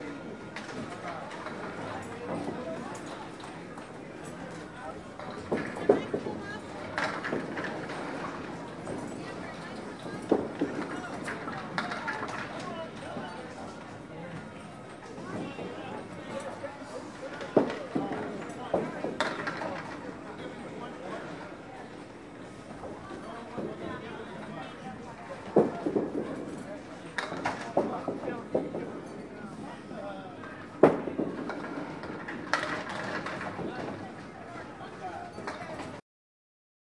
波士顿凯尔特人队，TD花园的氛围 " 波士顿花园凯尔特人队的氛围2
描述：TD花园，波士顿氛围
标签： 场记录 器官 喋喋不休 掌声 TD 室内 环境 欢呼 凯尔特人 体育 观众 看球 拍手 波士顿 欢呼 人群 花园 篮球 沃拉 竞技场
声道立体声